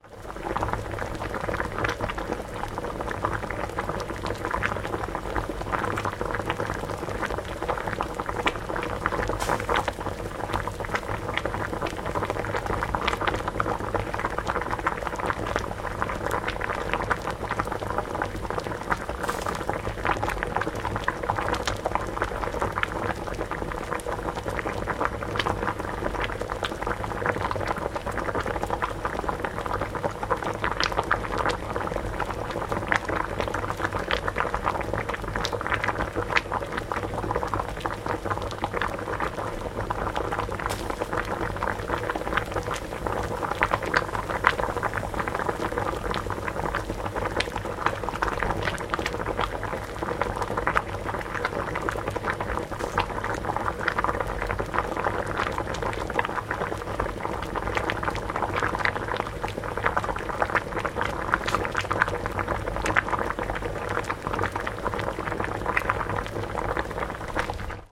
Шипение воды в кастрюле на газовой плите